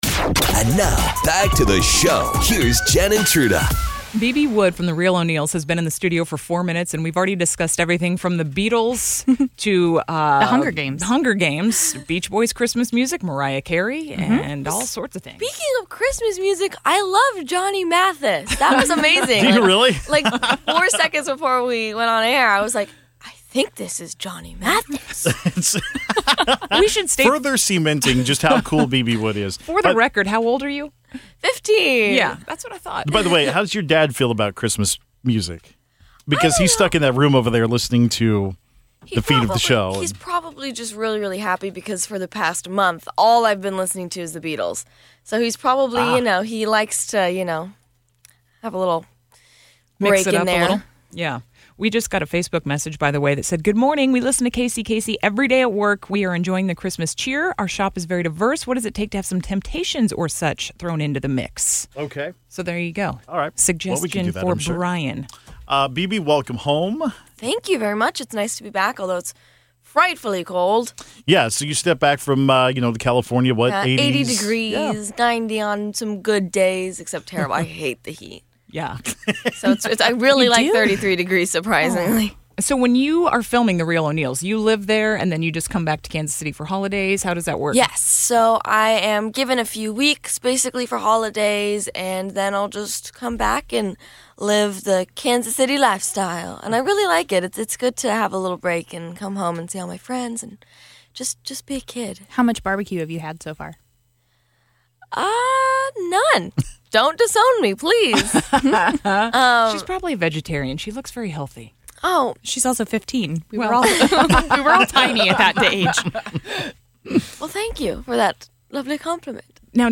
Bebe Wood from The Real O'Neals In Studio: SHOWcast 11/21/2016
And what else would she do than stop by the studio to co-host today's show and chat music, TV shows, the AMAs, weird family Thanksgiving stories, Stranger Things and so much more!